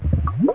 1 channel
bubble.mp3